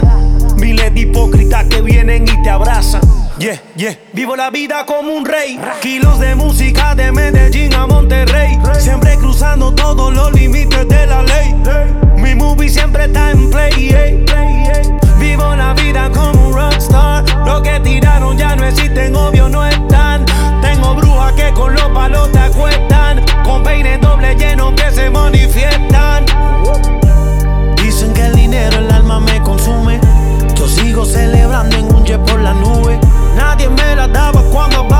Urbano latino Latin Hip-Hop Rap
Жанр: Хип-Хоп / Рэп / Латино